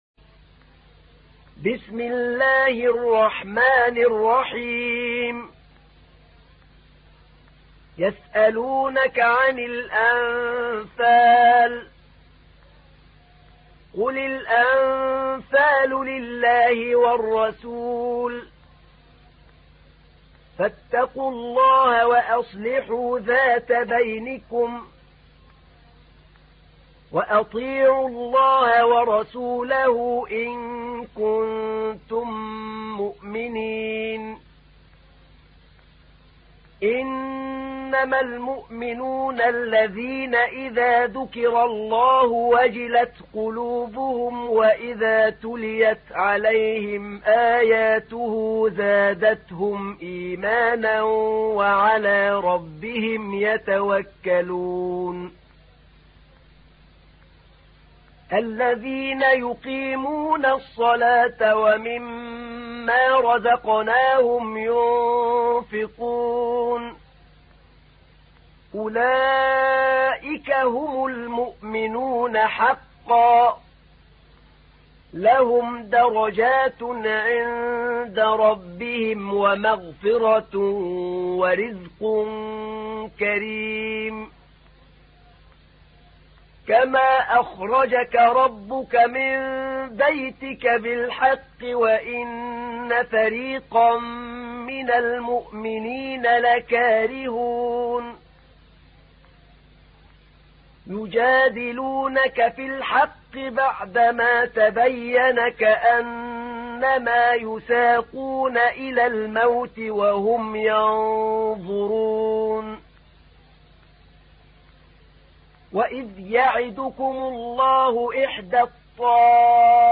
تحميل : 8. سورة الأنفال / القارئ أحمد نعينع / القرآن الكريم / موقع يا حسين